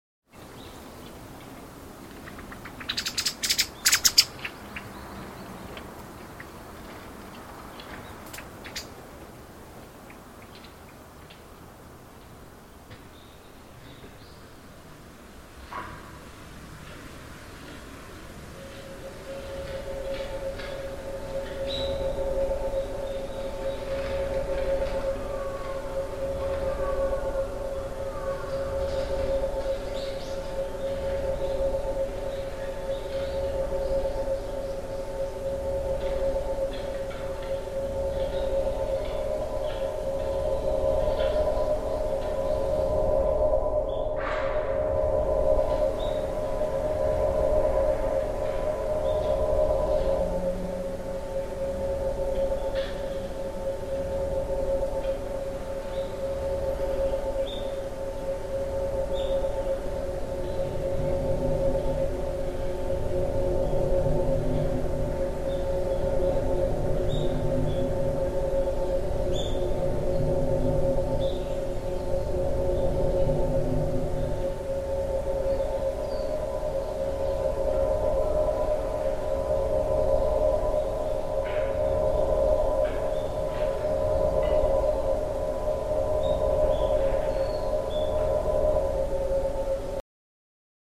field recordings of Kobe along with a gentle synthesizer